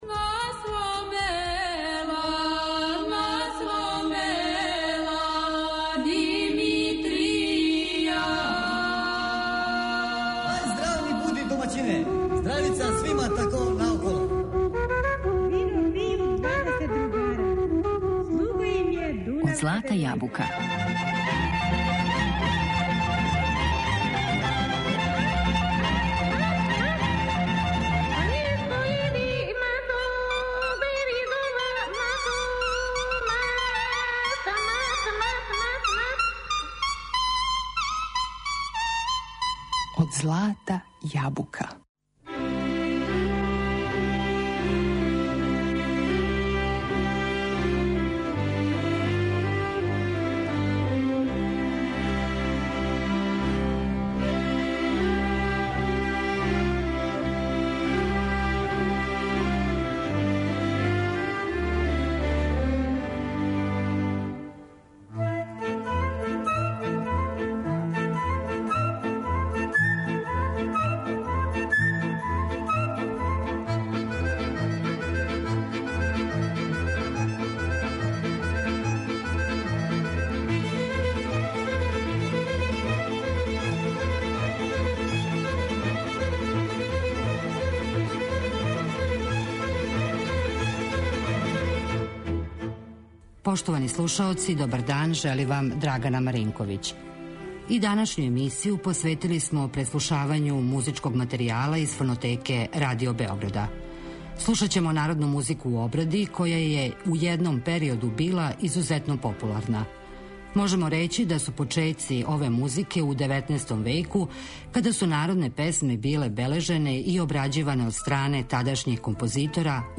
У данашњој емисији настављамо шетњу кроз Тонски архив Радио Београда и слушамо народну музику у обради, која је у једном периоду била популарна.
Чућемо аранжмане за Велики Народни оркестар које су приредили Ђорђе Караклајић